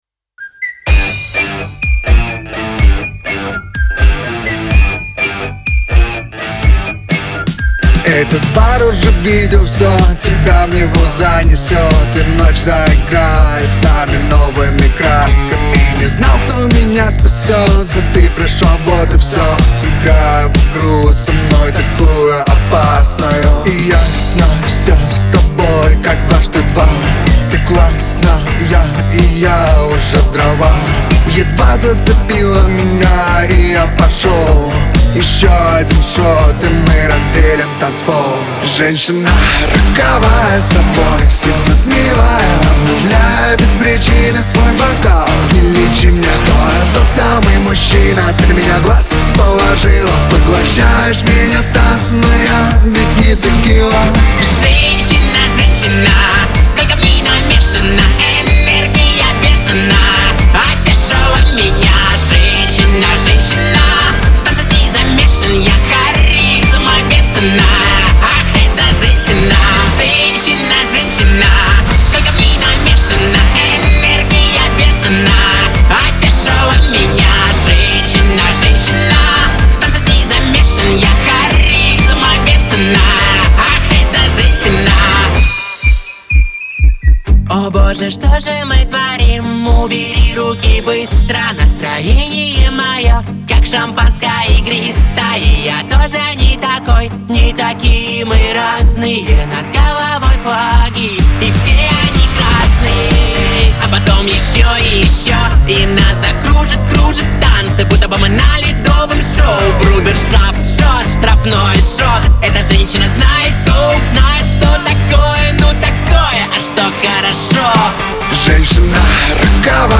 дуэт
эстрада
pop